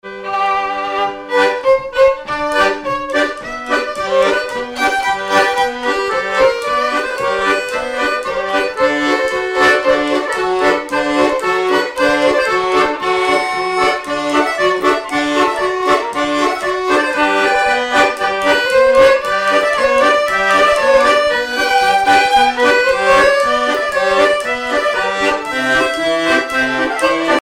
Miquelon-Langlade
danse : marche
violon
Pièce musicale inédite